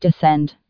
TCAS voice sound samples. ... Artificial female voice.
descend.wav